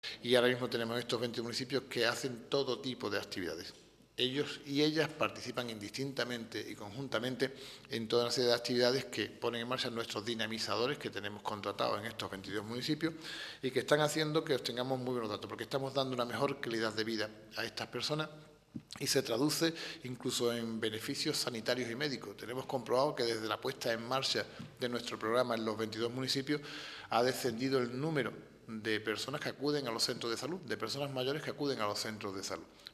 Antonio García explica el programa
Así lo ha anunciado hoy en rueda de prensa el diputado responsable de área, Antonio García Ortega.